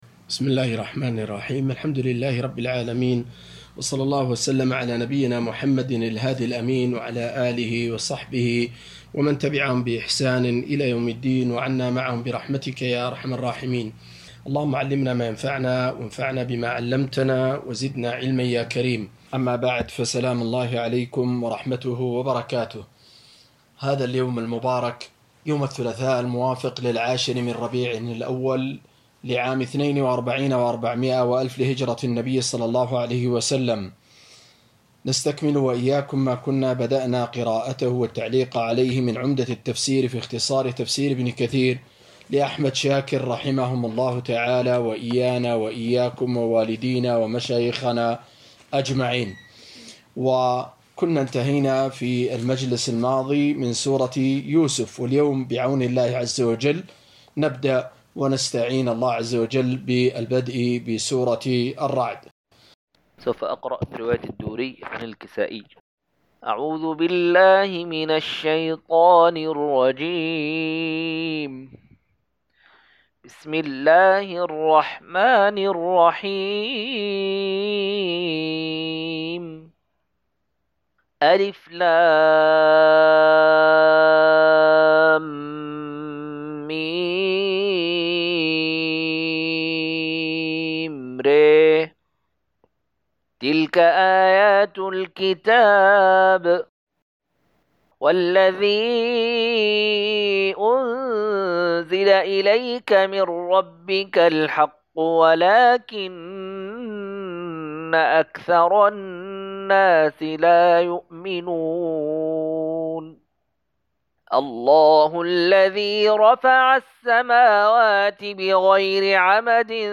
234- عمدة التفسير عن الحافظ ابن كثير رحمه الله للعلامة أحمد شاكر رحمه الله – قراءة وتعليق –